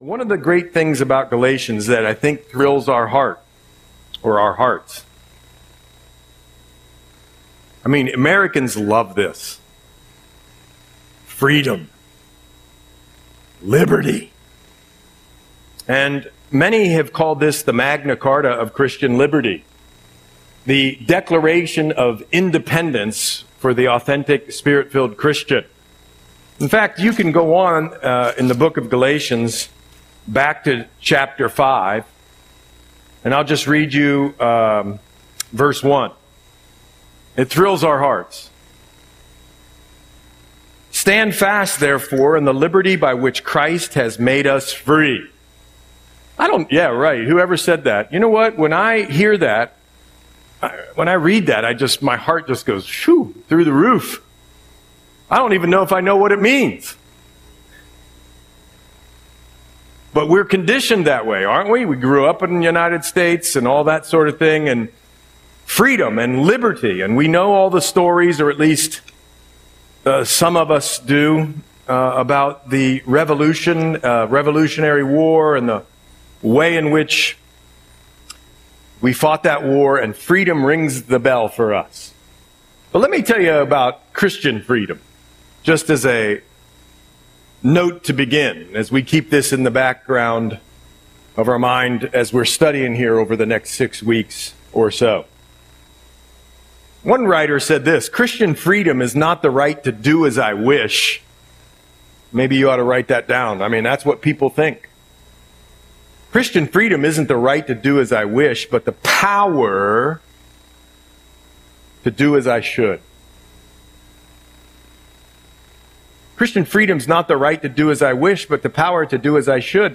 Audio Sermon - May 18, 2025